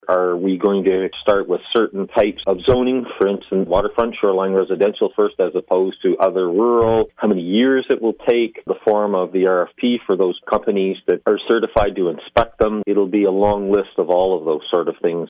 Council decided to go with the most thorough program and Mayor Brent Devolin explains what a type four inspection is.